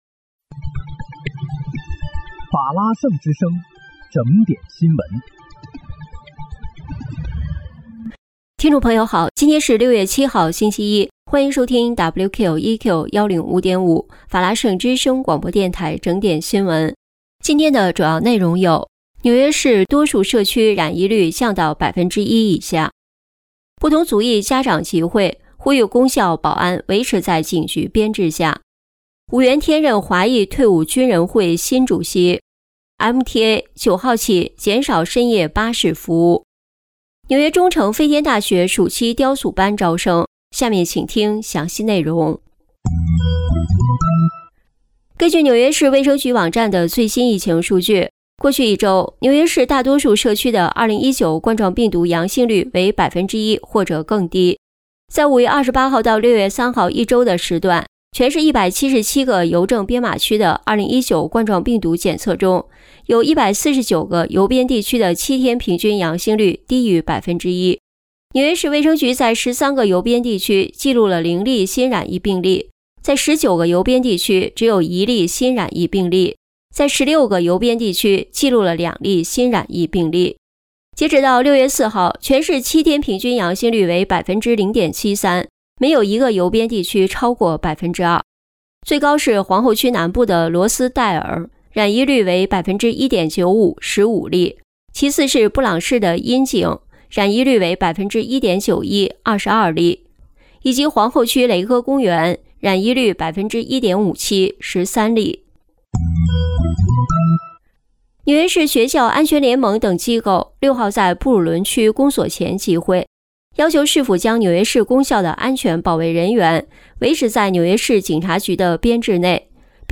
6月7日（星期一）纽约整点新闻
听众朋友您好！今天是6月7号，星期一，欢迎收听WQEQ105.5法拉盛之声广播电台整点新闻。